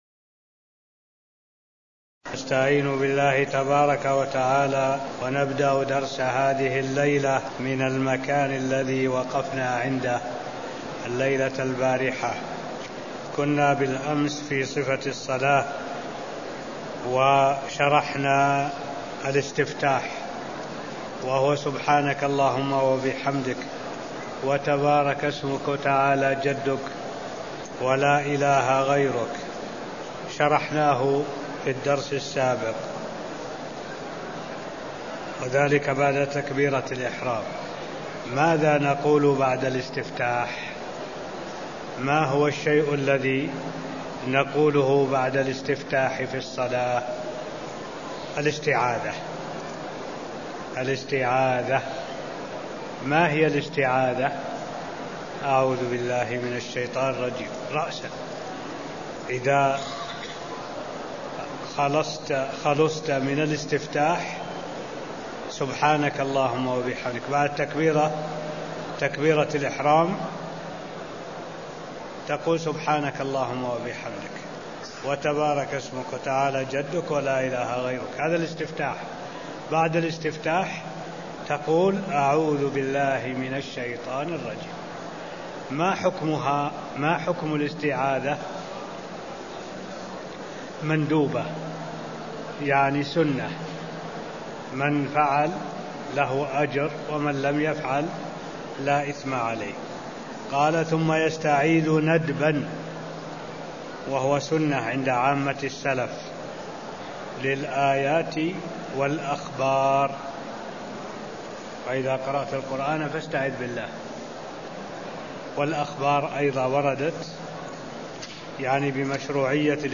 المكان: المسجد النبوي الشيخ: معالي الشيخ الدكتور صالح بن عبد الله العبود معالي الشيخ الدكتور صالح بن عبد الله العبود صفة الصلاة ص24 (0032)